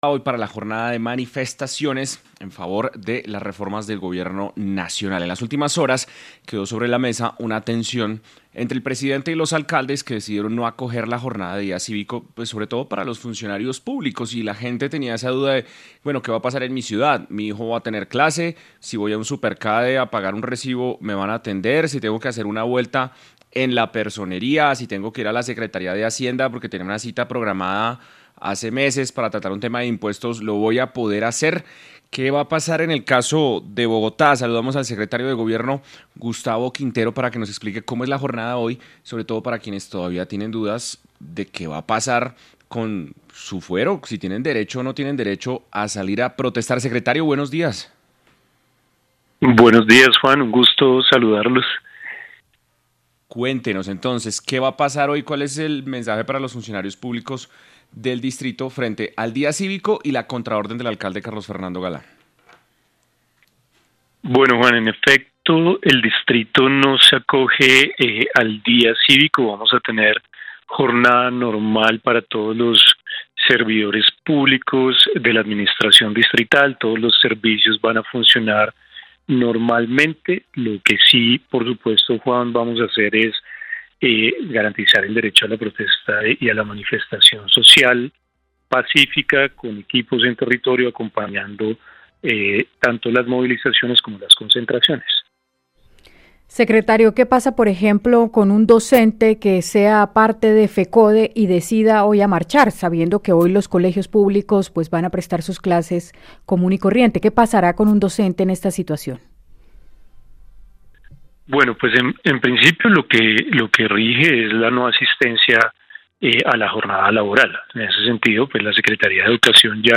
Gustavo Quintero, secretario de Gobierno de Bogotá explicó en 6AM cómo garantizarán la seguridad de los marchantes y qué pasará con los maestros que participen, tras la advertencia del alcalde Galán.